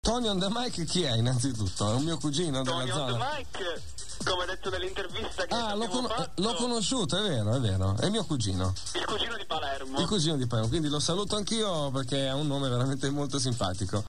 Albertino speaks about me on Deejay time (in italian)